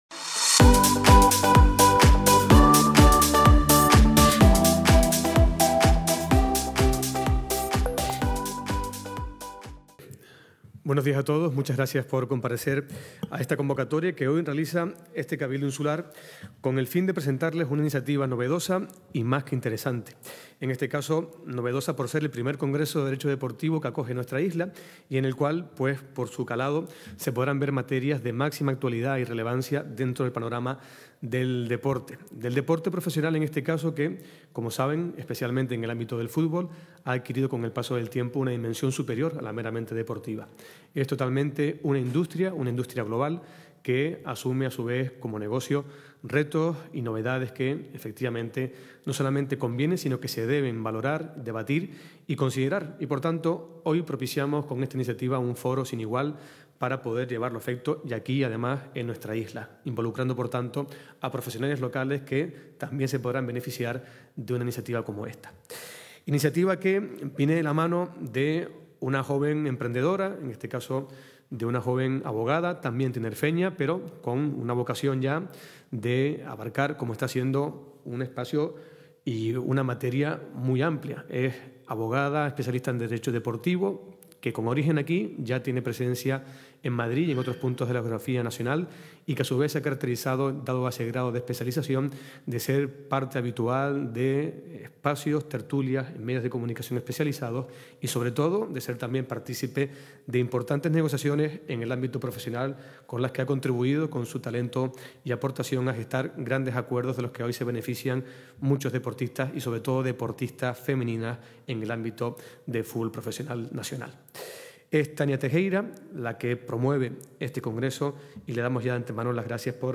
Recientemente emitido: Desde el Salón Noble del Cabildo de Tenerife, a partir de las 11.00 horas, presentación del I Congreso de Derecho Deportivo Isla de Tenerife.